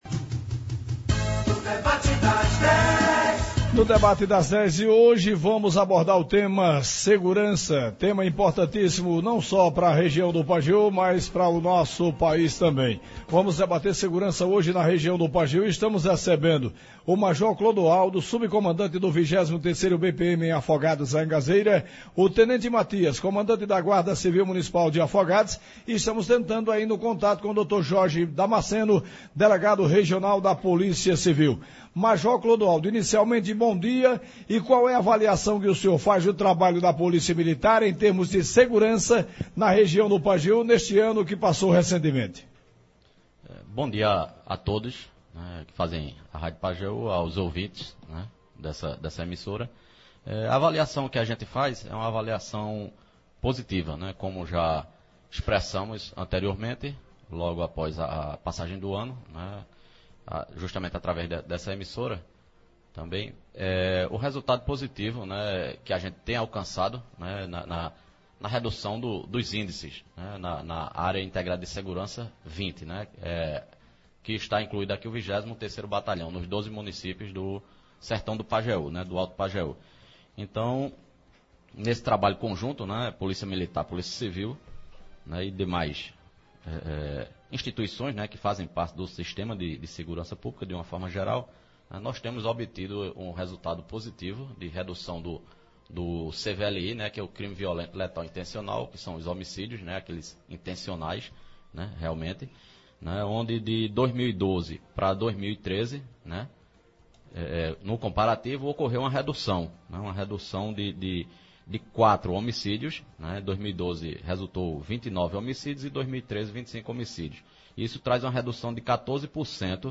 Debate das Dez: a segurança pública de Afogados da Ingazeira e região – Rádio Pajeú